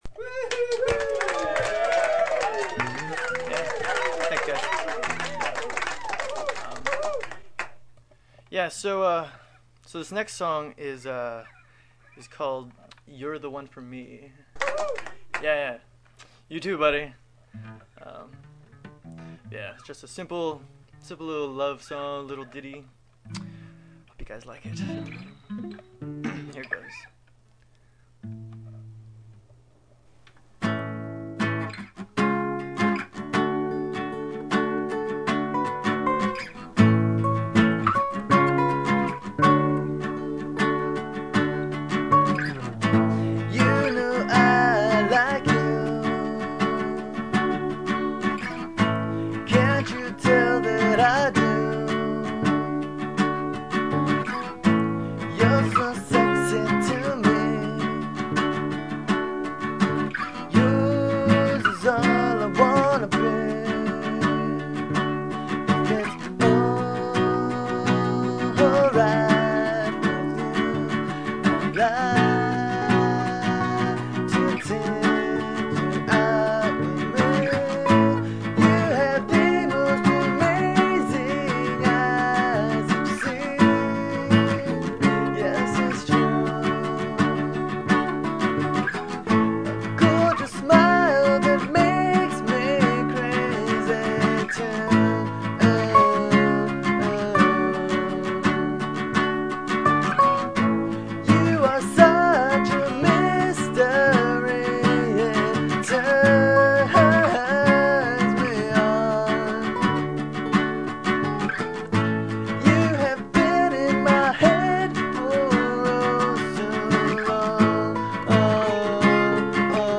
recorded live at the Belbury Stage